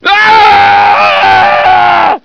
scream05.ogg